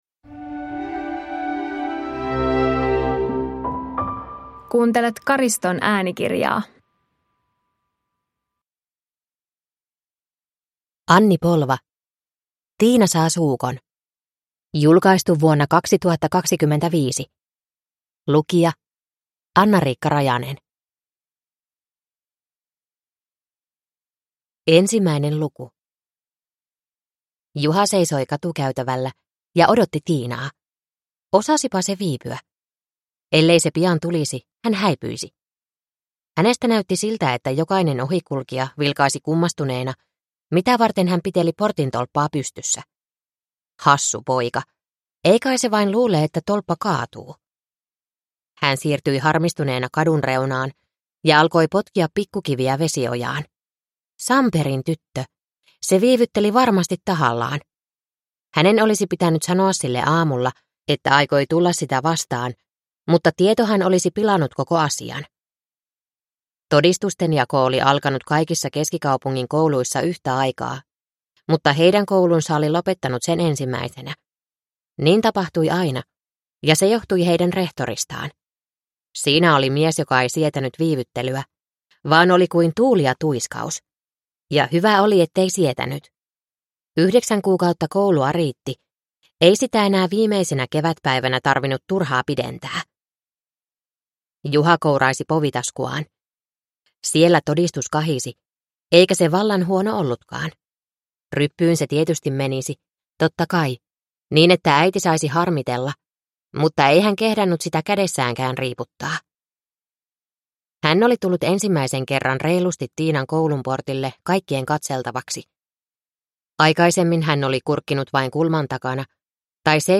Tiina saa suukon (ljudbok) av Anni Polva